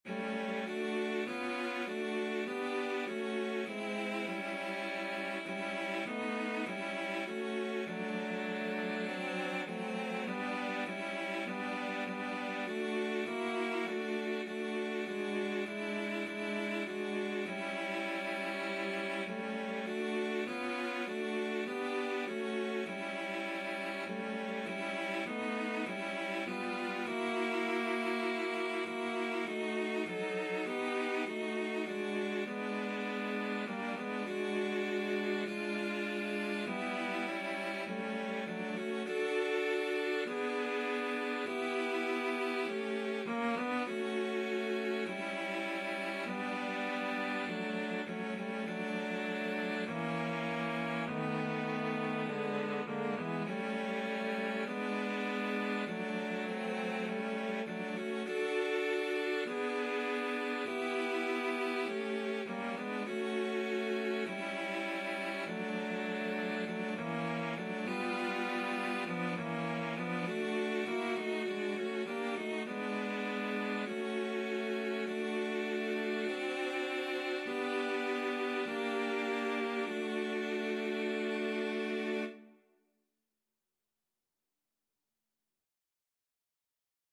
Traditional (View more Traditional Cello Trio Music)